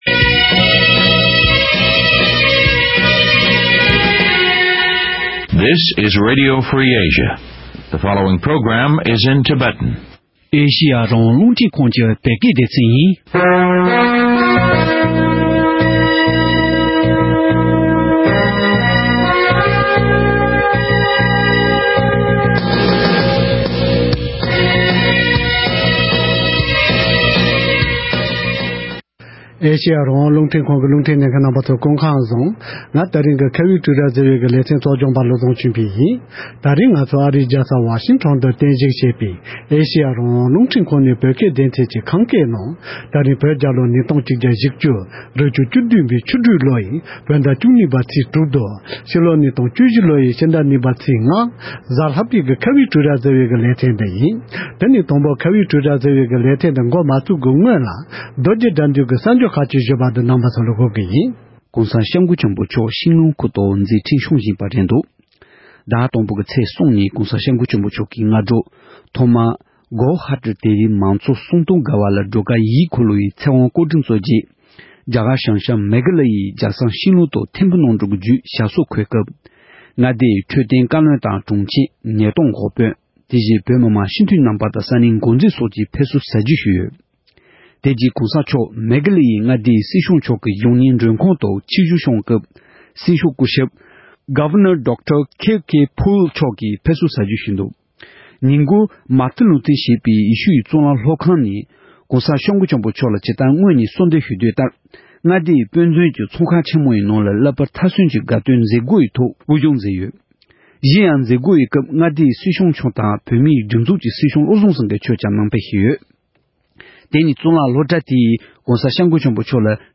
༄༅། །ཐེངས་འདིའི་ཁ་བའི་གྲོས་རྭ་ཞེས་པའི་ལེ་ཚན་ནང་། དབུ་མའི་ལམ་མི་མང་ལས་འགུལ་ཁང་གིས་ཕྱི་ལོ་༢༠༡༤ཟླ་༤ཚེས༢༥ཉིན་རྒྱ་གར་རྒྱལ་ས་ལྡི་ལིའི་ནང་ལས་འགུལ་ཞིག་སྤེལ་གྱི་ཡོད་པ་ལྟར། ལས་འགུལ་དེའི་དམིགས་ཡུལ་དང་། རྒྱུ་མཚན་སོགས་ཀྱི་སྐོར་འབྲེལ་ཡོད་དང་གླེང་མོལ་ཞུས་པར་གསན་རོགས་གནང་།།